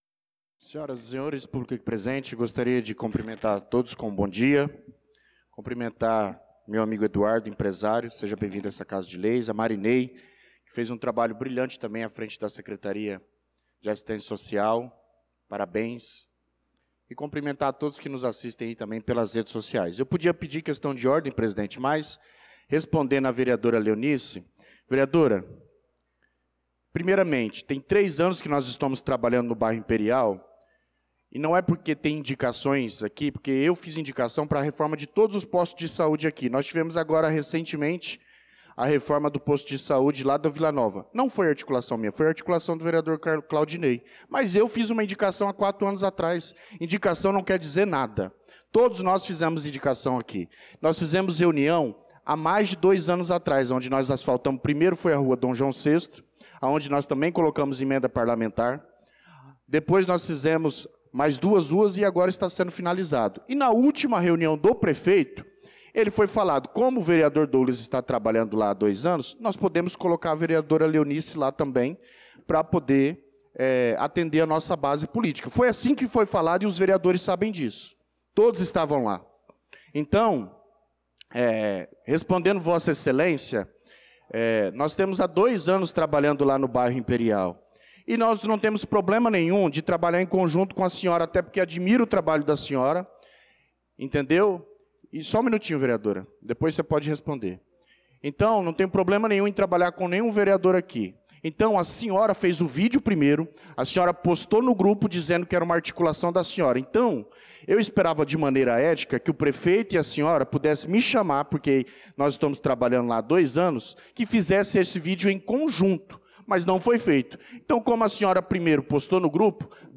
Pronunciamento do vereador Douglas Teixeira na Sessão Ordinária do dia 02/06/2025